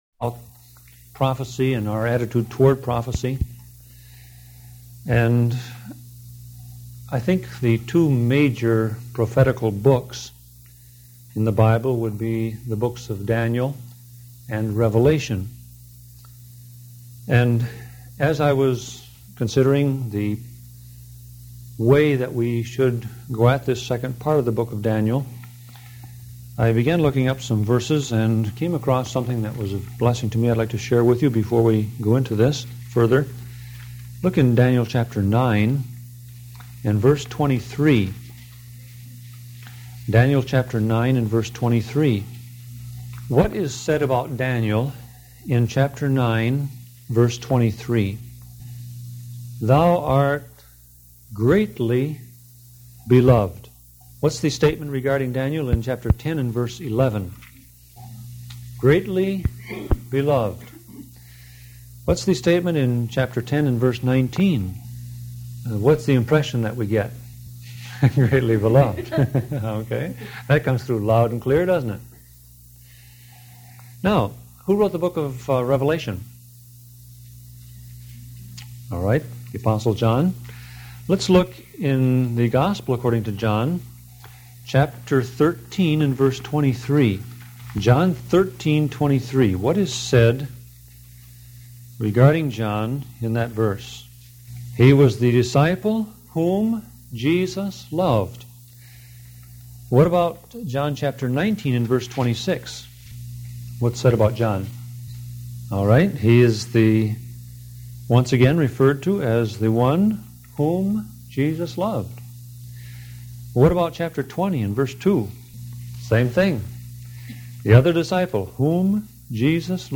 Sermon Audio Passage: Daniel 7:12-28:12 Service Type